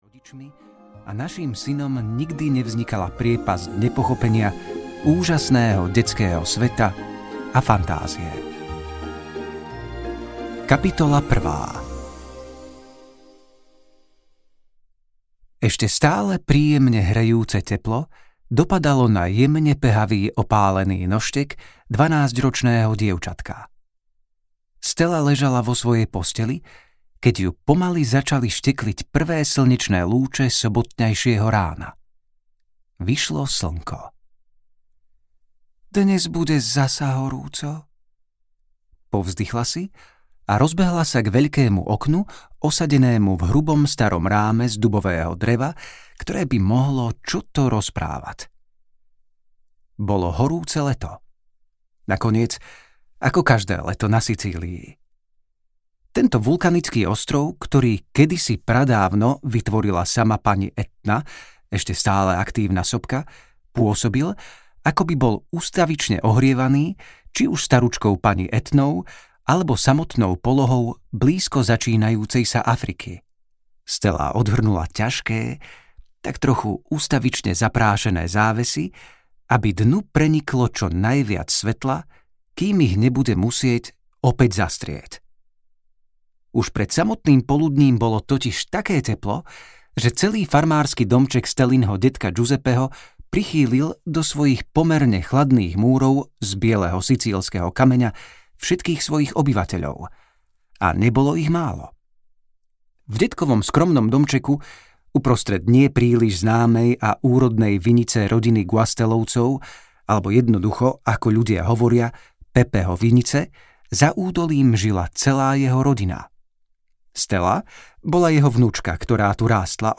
Polnočný jednorožec audiokniha
Ukázka z knihy
polnocny-jednorozec-audiokniha